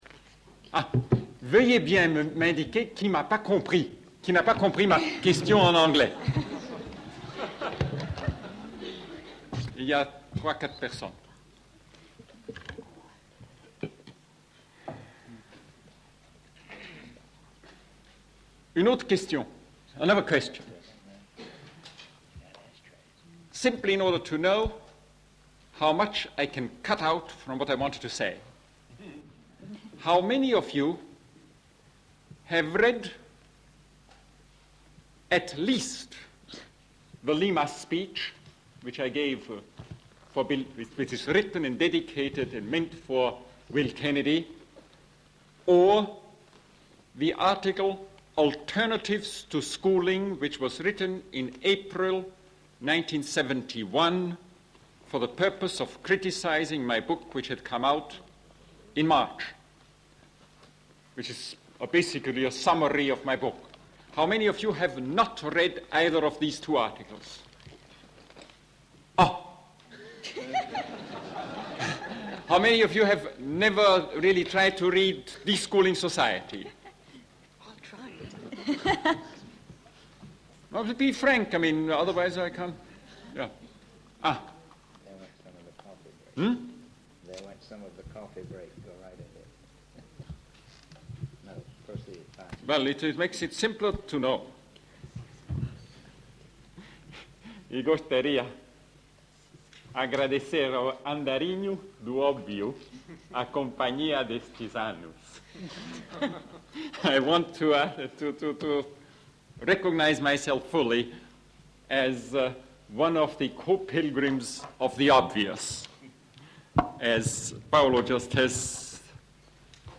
Ivan Illich – Extrait du séminaire Paulo Freire et Ivan Illich – An invitation to conscientization and deschooling: a continuing conversation (6 septembre 1974)
freireillichseminarillich.mp3